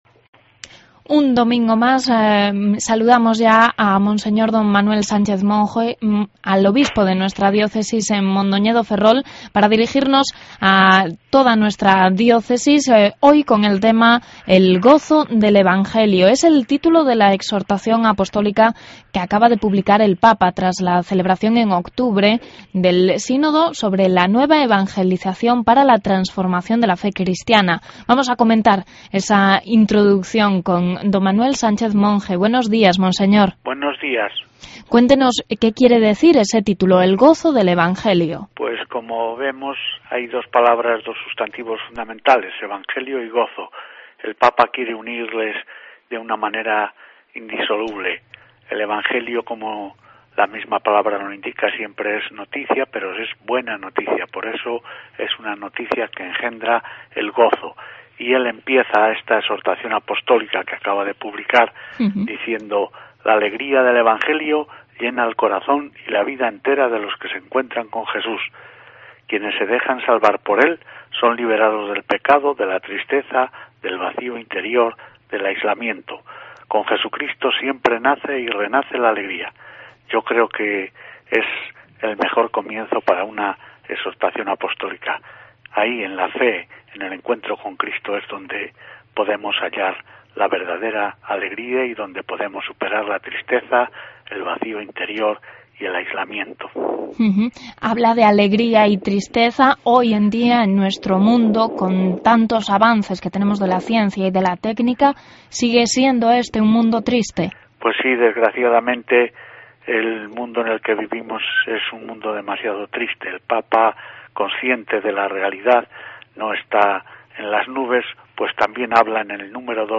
El obispo de la Diócesis de Mondoñedo-Ferrol, monseñor D. Manuel Sánchez Monge, nos habla de la última exortación apostólica publicada por el Papa Francisco: El Gozo del Evangelio.